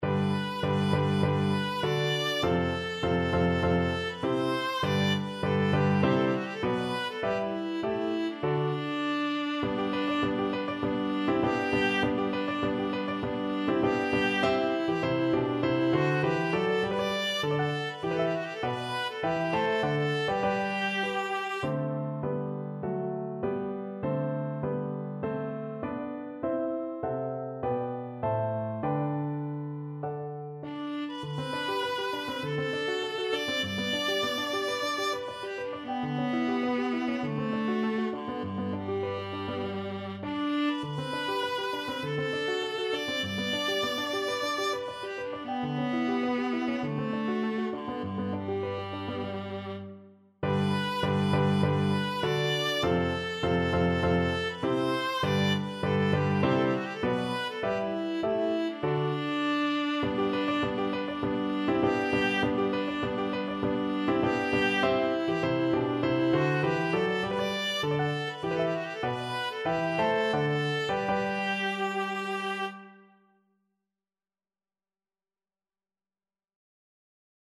4/4 (View more 4/4 Music)
Allegro guerriero =100 (View more music marked Allegro)
Classical (View more Classical Viola Music)